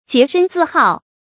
jié shēn zì hào
洁身自好发音
成语正音好，不能读作“hǎo”。